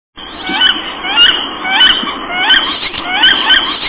Karolinka - Aix Sponsa
głosy